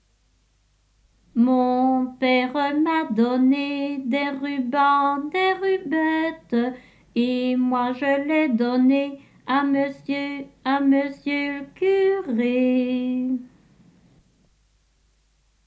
Genre : chant
Type : chanson de jeu
Lieu d'enregistrement : Lantin (Juprelle)
Support : bande magnétique
Incipit : C C D E C D D D D G